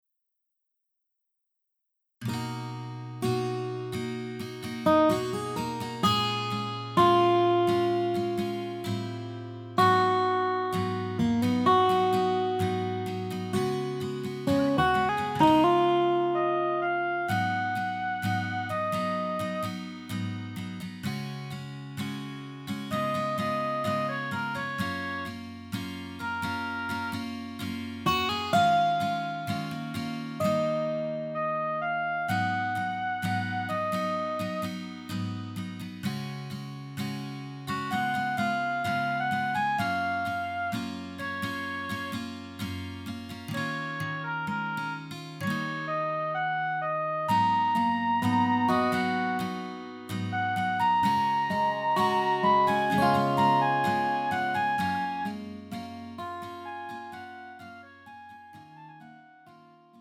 음정 원키 4:19
장르 가요 구분 Pro MR